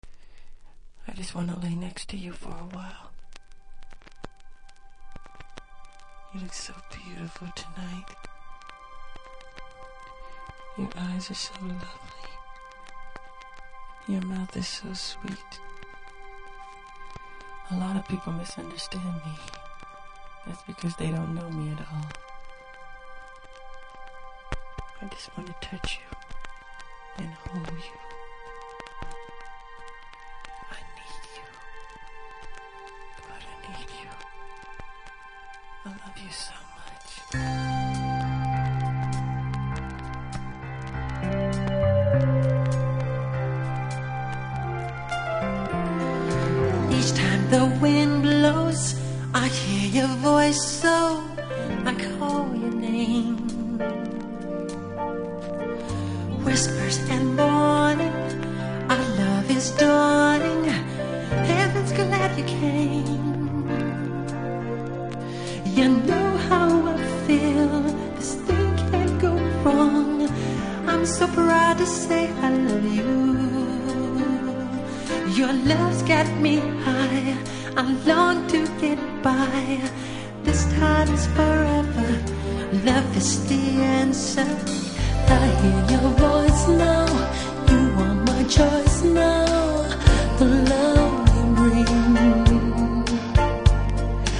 Vinyl
タイトル通りのデュエット・ラブソング♪
出だしの語り部分にノイズ感じますので試聴で確認下さい。